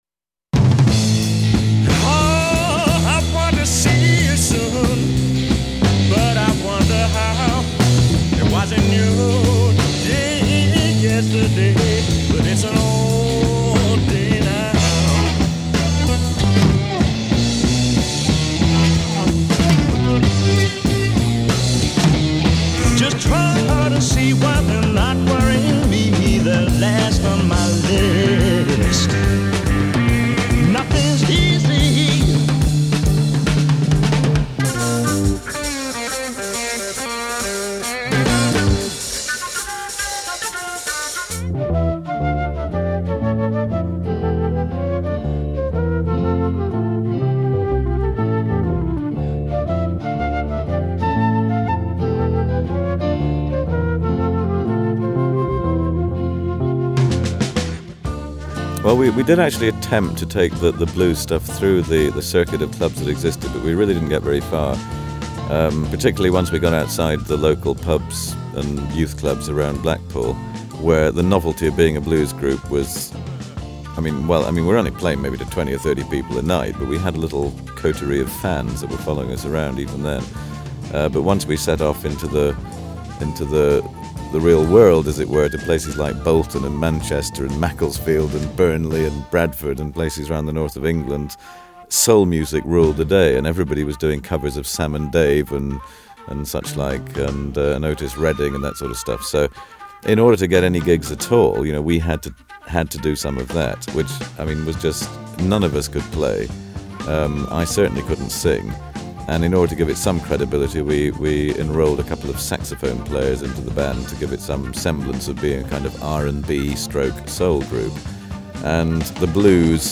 Jethro Tull Stand Up interview with Ian Anderson In the Studio